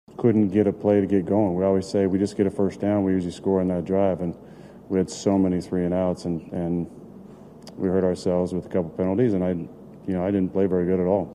Rodgers said he had a very bad night leading the offense.